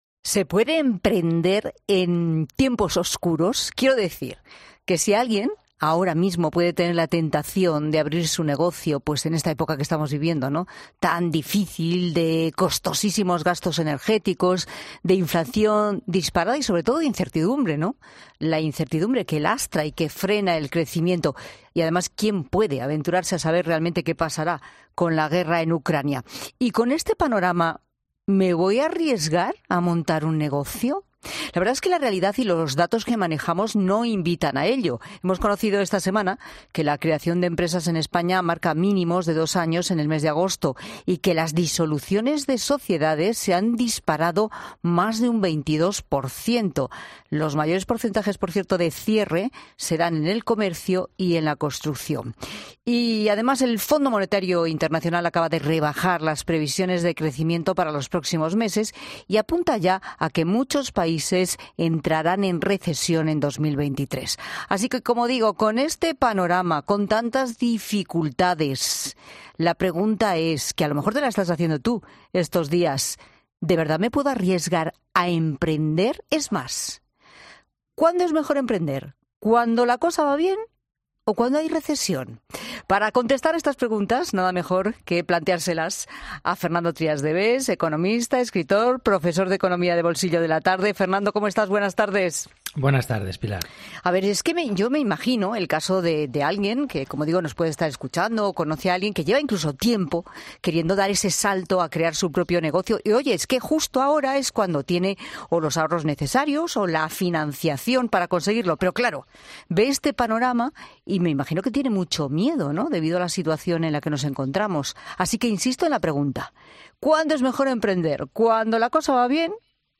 El economista Trías de Bes pone sobre la mesa en en 'La Tarde' las cifras que te podrían hacer tomar la decisión de emprender o no en tiempos de crisis